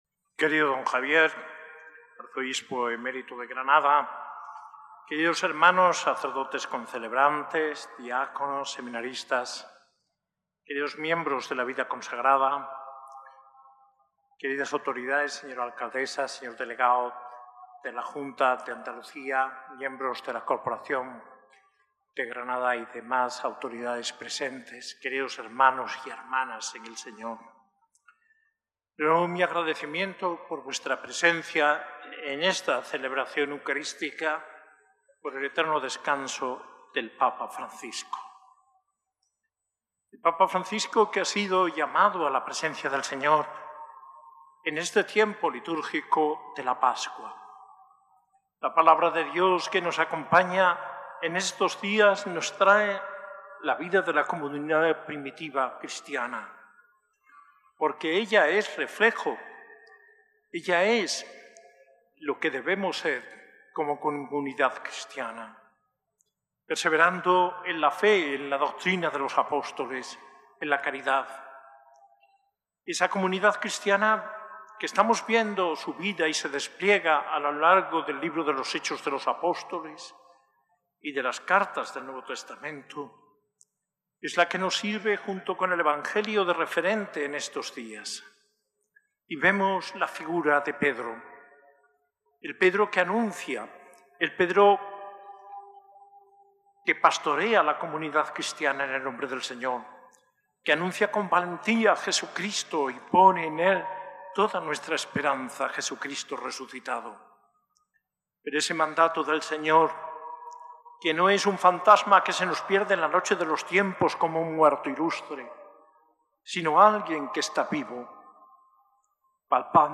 Homilía del arzobispo de Granada, Mons. José María Gil Tamayo, en la Eucaristía por el fallecimiento del Santo Padre Francisco, el 24 de abril de 2025, en la S.A.I Catedral.